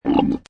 Camel Sound Effect Free Download
Camel